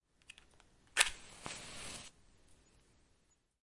描述：从它的盒子里抓住一根火柴的声音，然后启发并燃烧它。
标签： 开导 火柴 火焰 裂纹 火柴 火焰 燃烧